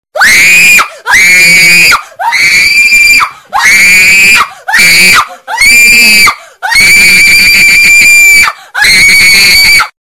Звуки крика людей
Осторожно, громкий крик дикой девушки